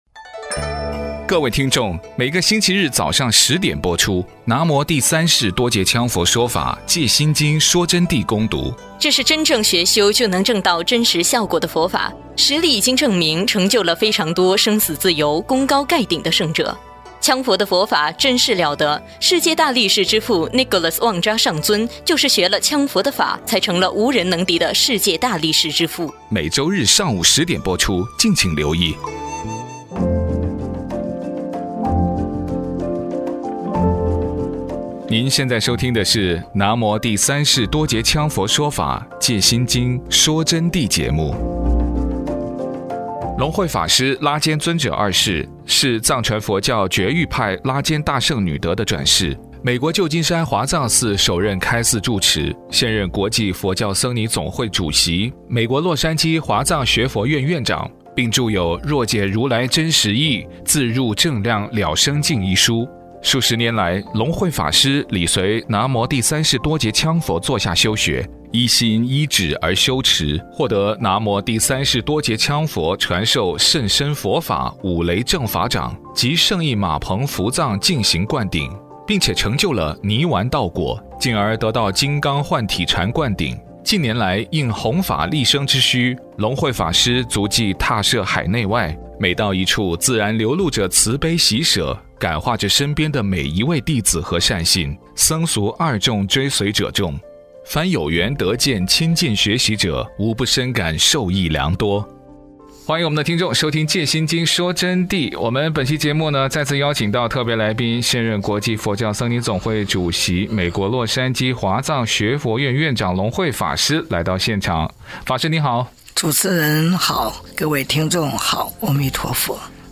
佛弟子访谈（三十七）浅谈因果的概念与真正的「胜义浴佛法会」现场实况